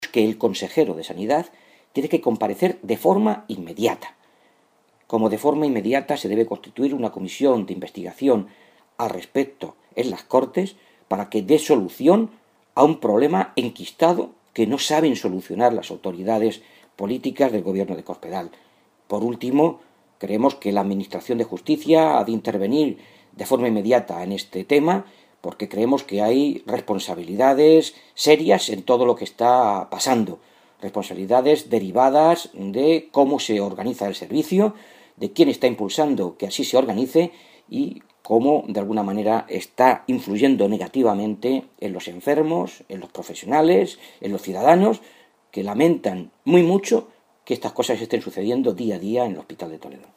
El portavoz de Sanidad del Grupo Socialista, Fernando Mora, exige la creación de una comisión de investigación para analizar y dar soluciones de una vez al deterioro constante que se está dando en este servicio del centro hospitalario toledano
Cortes de audio de la rueda de prensa